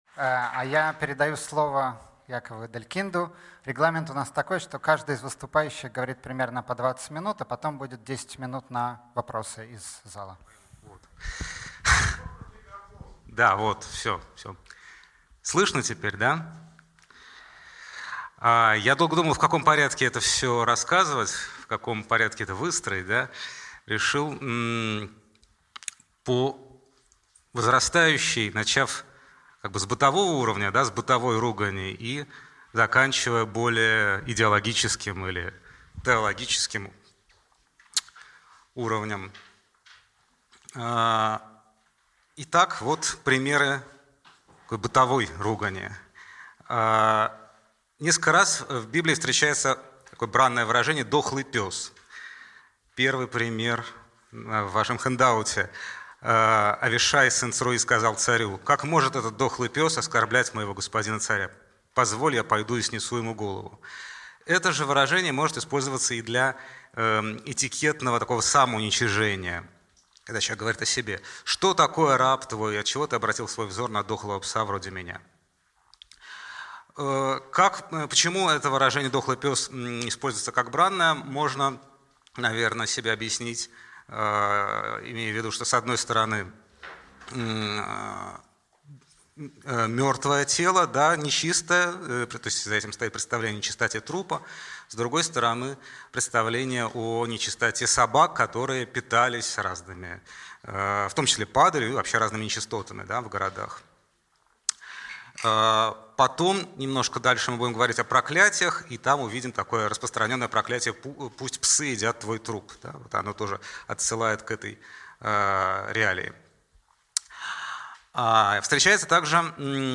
Аудиокнига Обсценная лексика в иврите | Библиотека аудиокниг